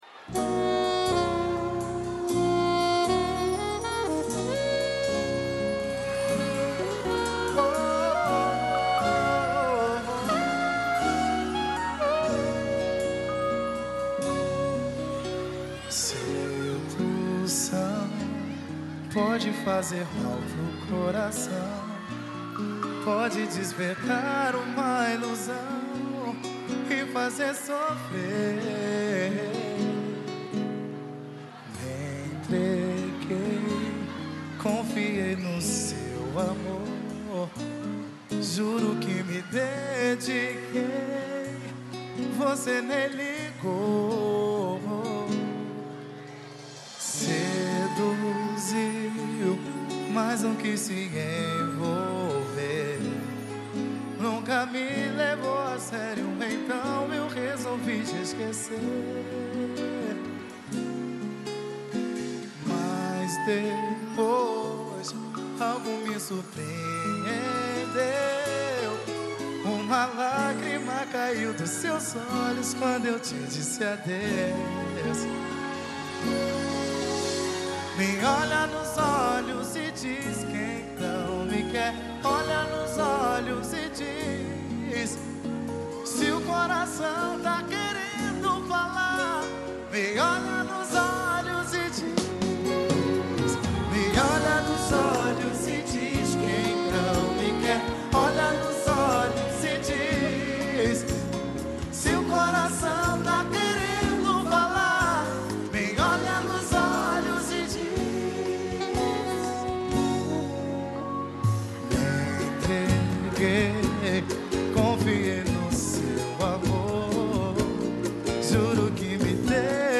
pagode